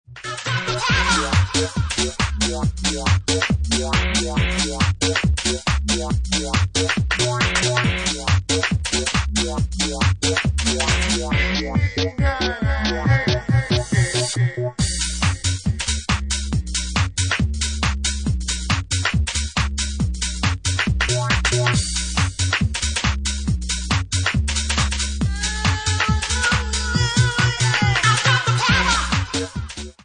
Bassline House at 139 bpm